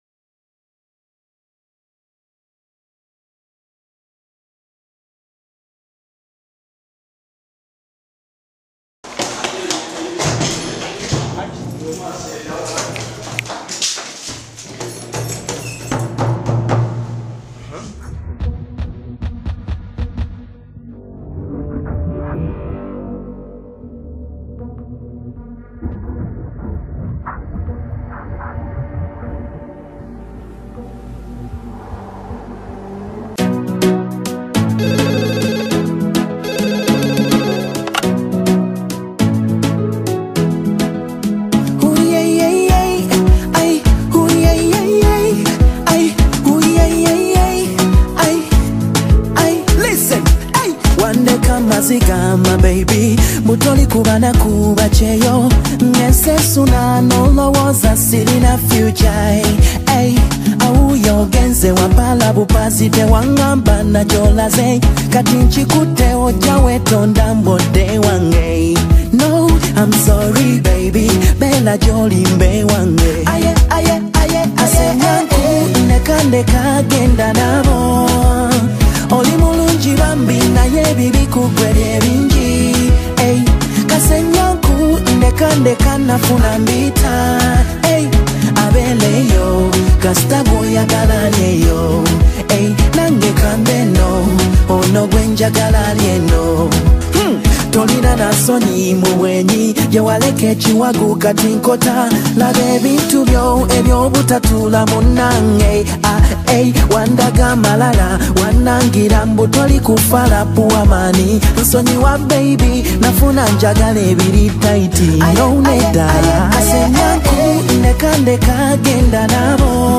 • Genre: Ugandan R&B / Soul